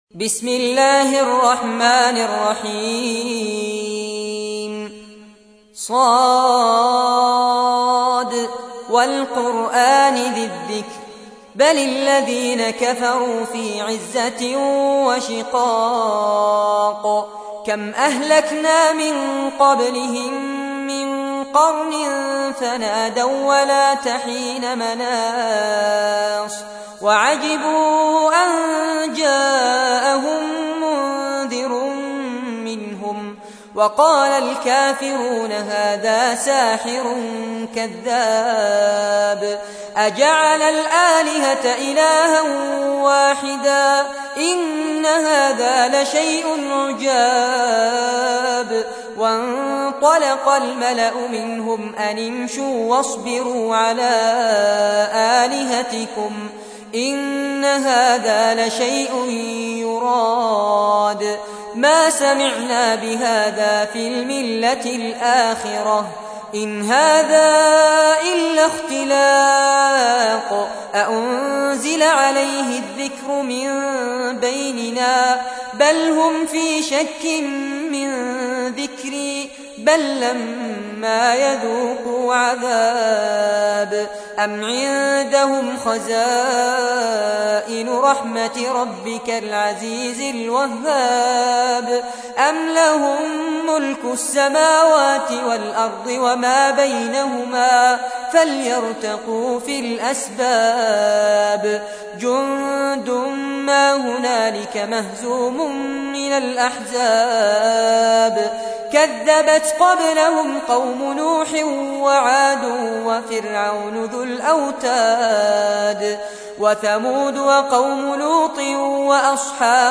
38. سورة ص / القارئ